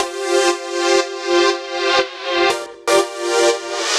Index of /musicradar/french-house-chillout-samples/120bpm
FHC_Pad B_120-C.wav